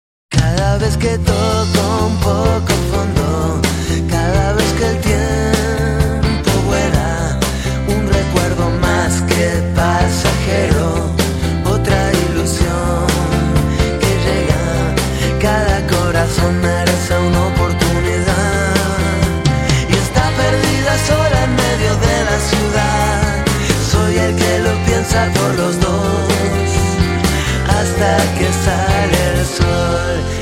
ROCK EN ESPAÑOL